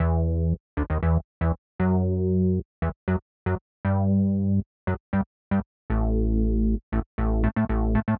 11 Bass PT4.wav